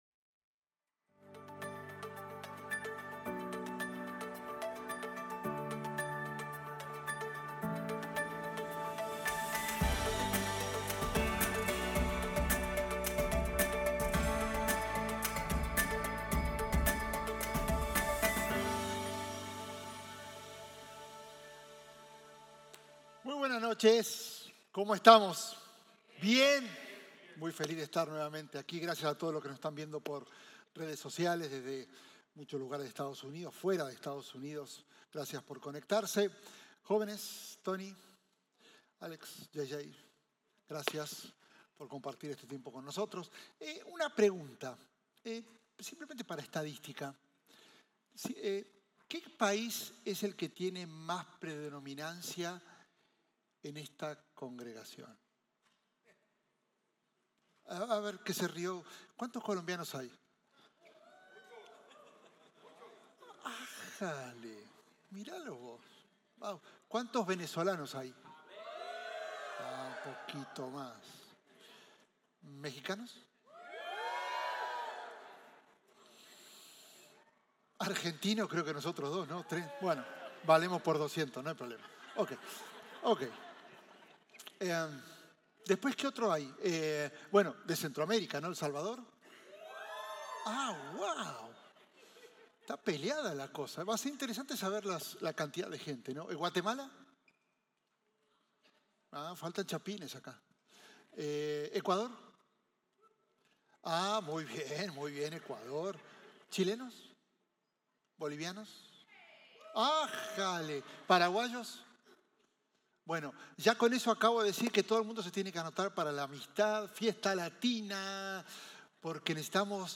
Un mensaje de la serie "Más - NK."